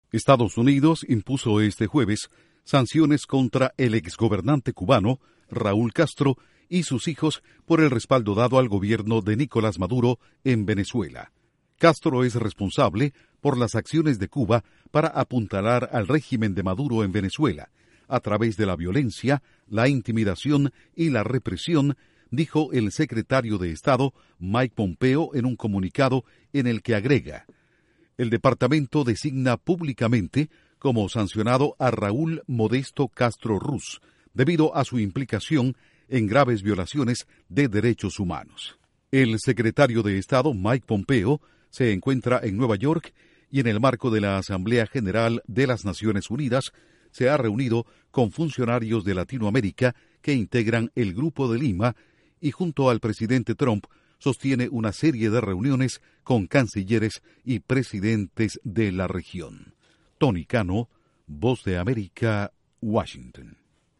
Estados Unidos sanciona a Raúl Castro por respaldar a Maduro en Venezuela y por violaciones a derechos humanos. Informa desde la Voz de América en Washington